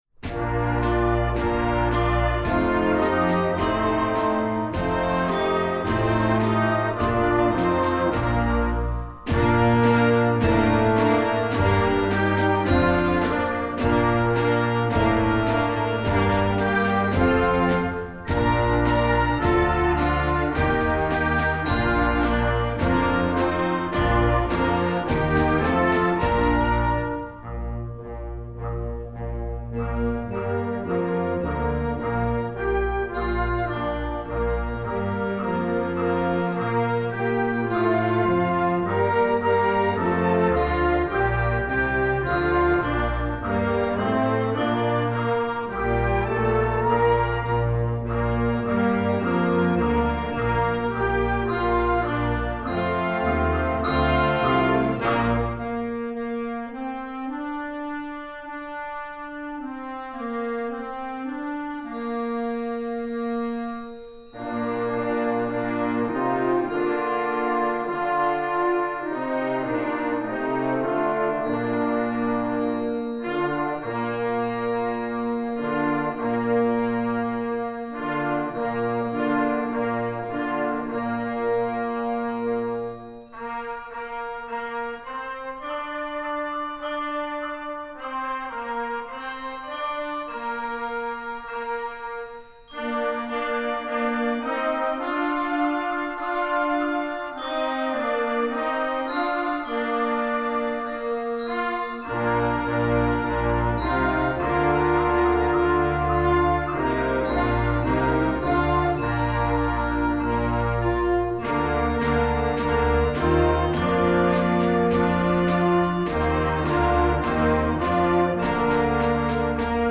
instructional, american, children